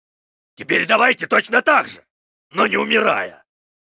Вместо этого предлагаем ознакомиться с новыми звуковыми файлами из игрового клиента Heroes of the Storm, добавленными вместе с новым героем — Потерявшимися Викингами.